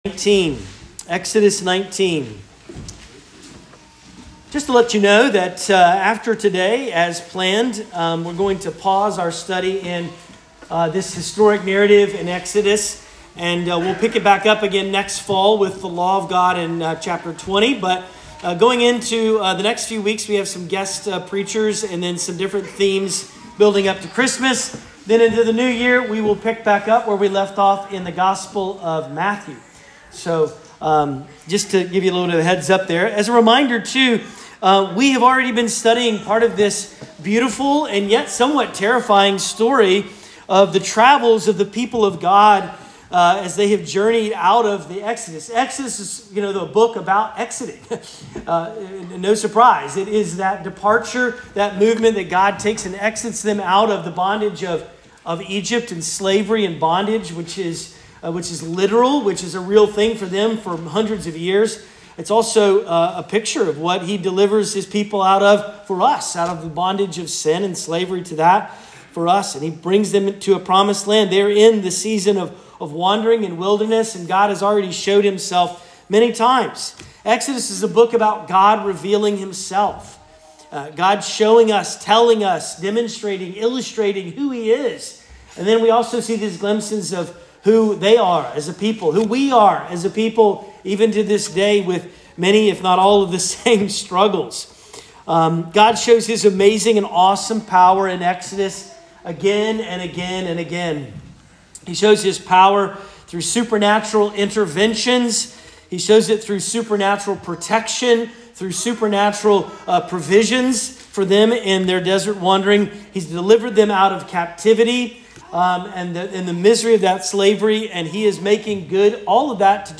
Sermon Audio
Sermons from Grace Presbyterian Church: South Shore of Boston